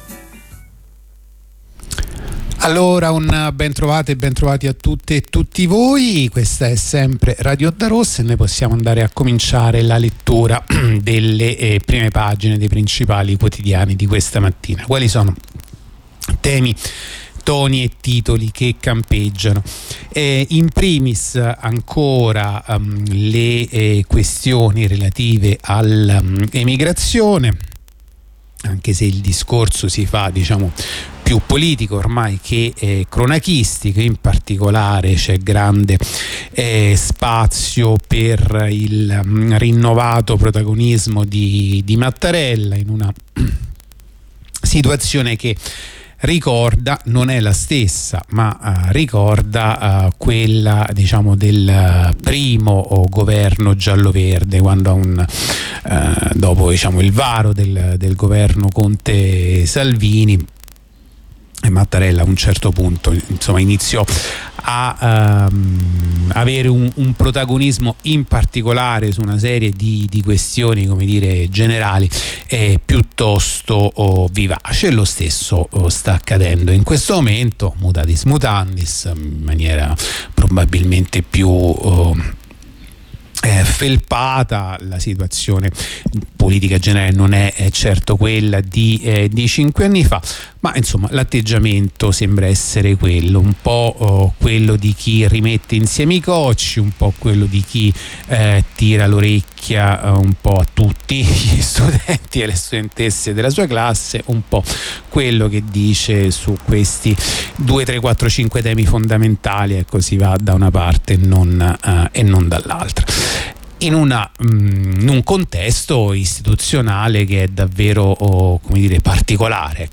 La rassegna stampa di radio onda rossa andata in onda martedì 7 marzo 2023